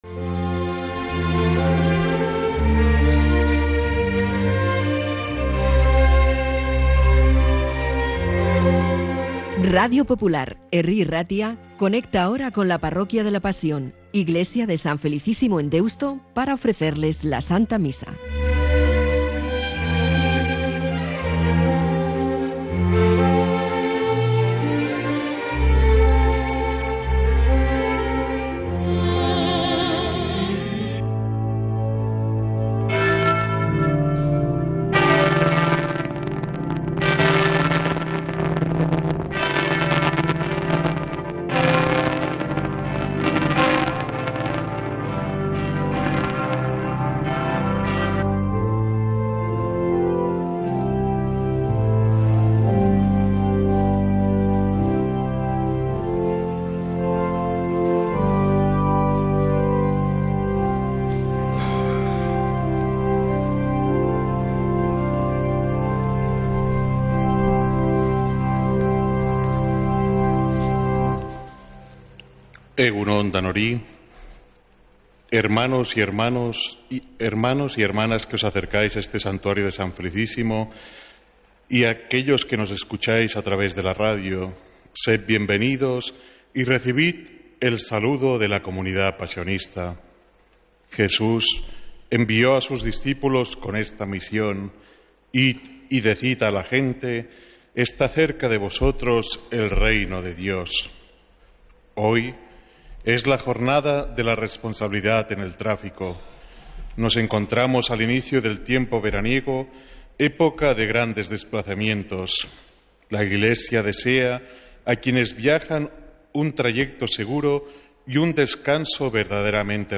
Santa Misa desde San Felicísimo en Deusto, domingo 6 de julio de 2025